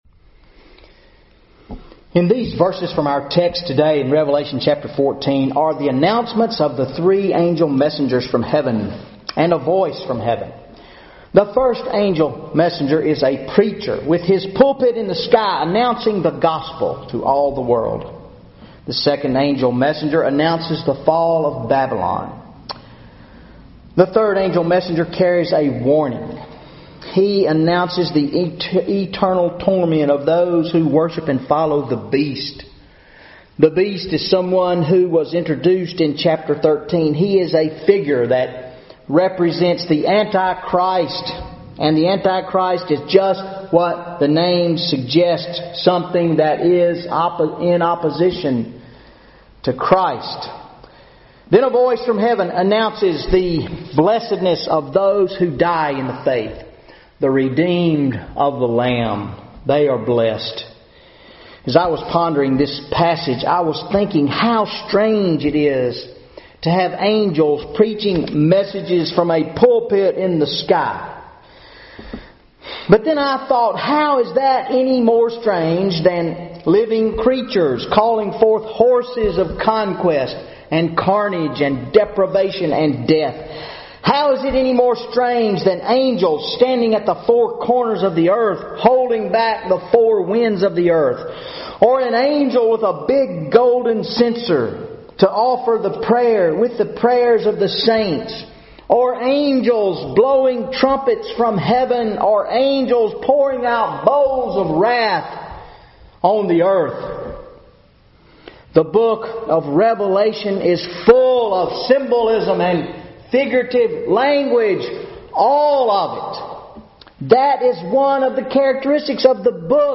Sunday Sermon December 28, 2014 Revelation 14:6-13 Four Timeless Messages for the Nations of the Earth